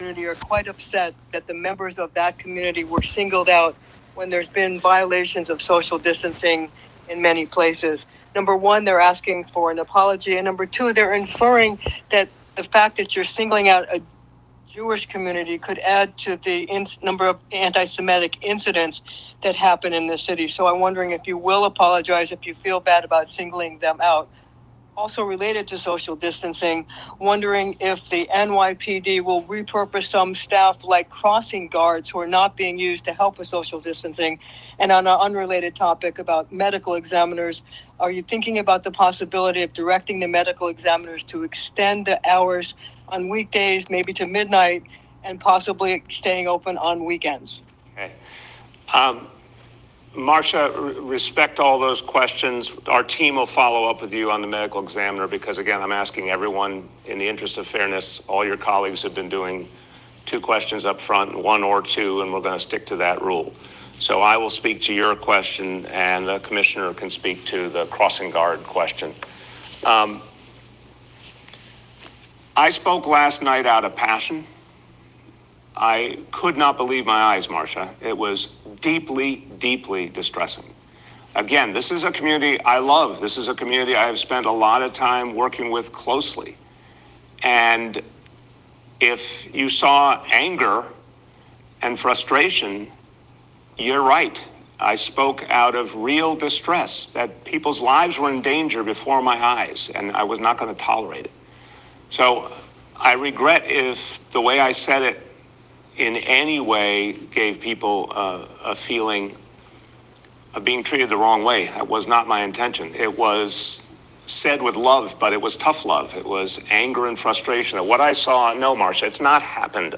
Mayor Deblasio spoke at a press conference on Wednesday morning, and tried to explain his tweets which have now gained national attention, and caused the Levaya of the Tola�as Yaakov Rebbe ZT�L to become national headlines.